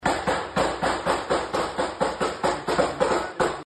cu-trasnoche-1-ambiente.mp3